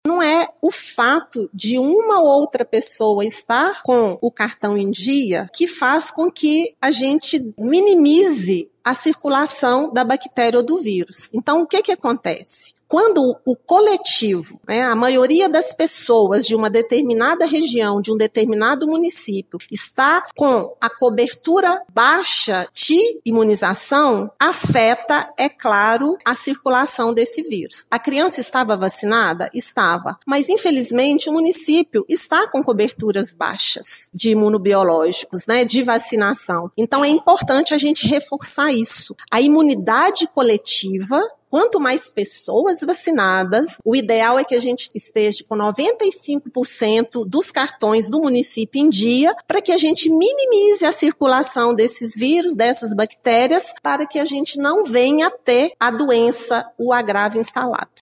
A informação foi repassada ontem durante coletiva de imprensa